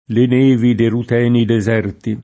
le n%vi de rut$ni de@$rti] (Zanella) — anche nome di antica popolazione gallica